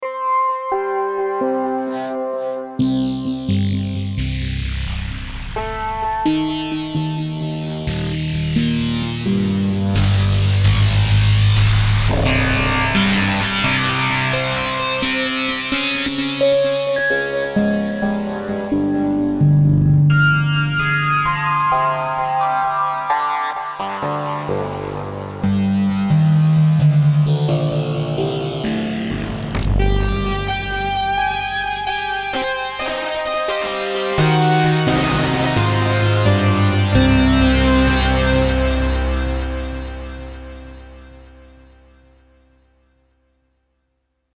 Lineare FM mit Wavetables hab ich eben schon mal fix gemacht.
Bei diesem Beispiel moduliert Osc2 mit einem Wavetable den Pitch von Osc1, der ebenfalls ein Wavetable spielt. Wavescan von Osc2 wird durch einen LFO moduliert, Velocity steuert Wavescan für Osc1 und Aftertouch steuert den FM-Amount. Zu hören ist nur Osc1 ohne Filter und ein wenig Delay: Anhang anzeigen 204489 Während der Aufnahme schalte ich das Wavetable für Osc1 (und Osc2) zufällig durch.